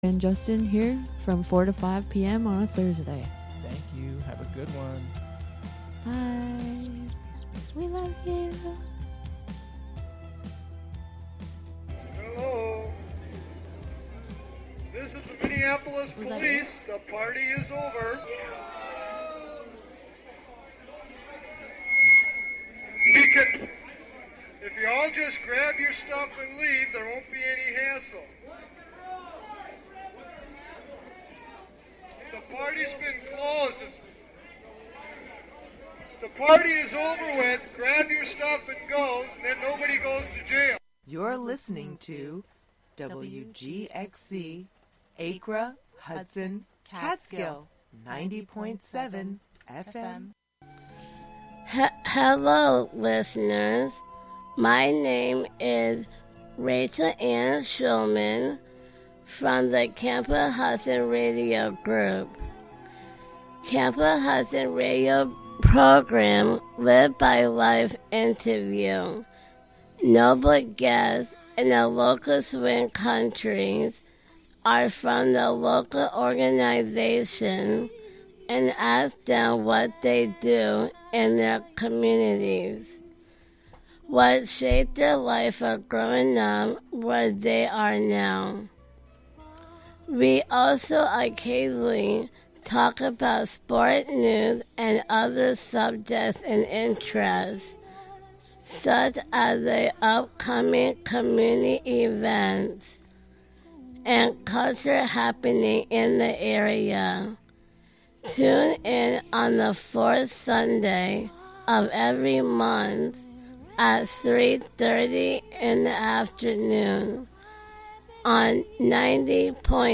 Broadcasting live from either The Spark of Hudson , the Hudson Thursday Market , or other locations out and about in the community.